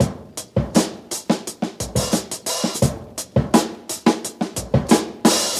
break